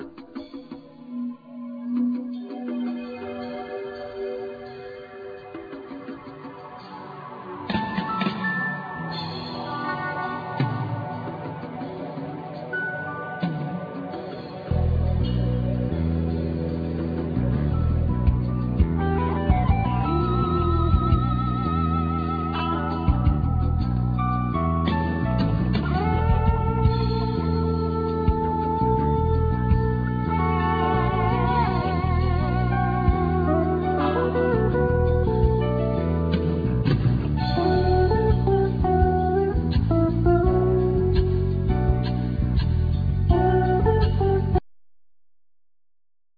Guitar,Keyboards
Drums
Keyboards,Piano,Voice
Percussion
Double Bass
Vocal